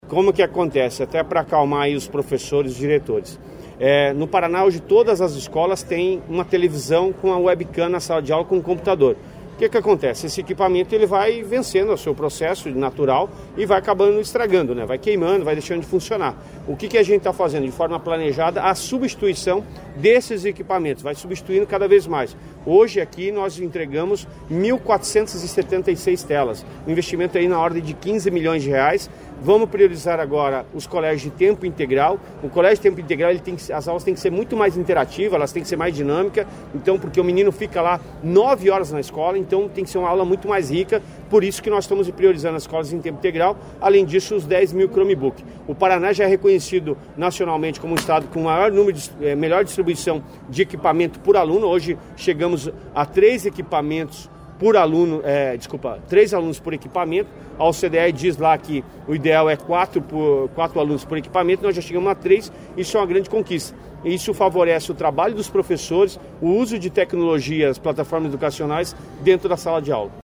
Sonora do secretário Estadual da Educação, Roni Miranda, sobre as novas telas e chromebooks entregues à rede estadual de ensino